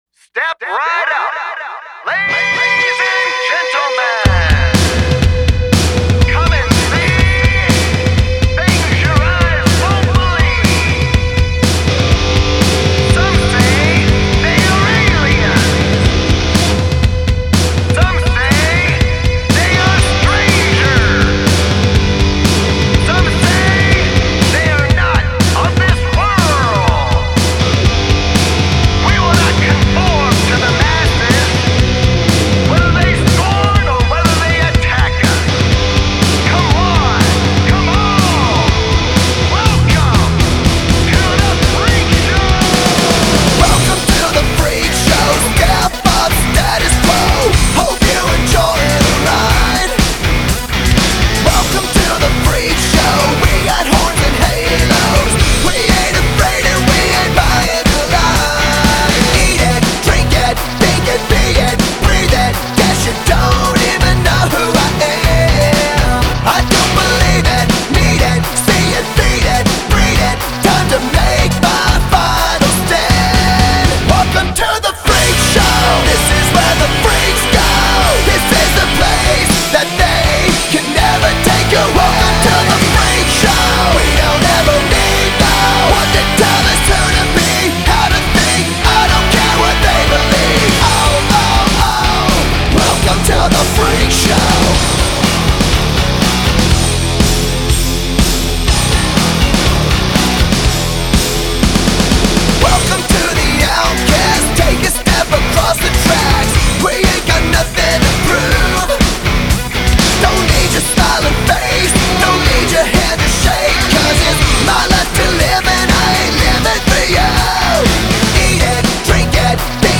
Hard Rock
rock music